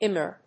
アイ‐エムアール